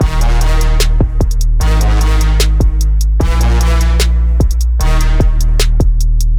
二声のフリジアンTSDT トラップビートを添えて
こちらのサンプルではあえて3rdを全く鳴らしていないのですが、パワーコードよろしくこうやって和声感を希薄にすると、フリジアンのケーデンスも何ら不自然なものには聴こえません。
フリジアンの3rdなしカデンツフリジアンこそが完全体？
2025-kpop-kadenz-trap.mp3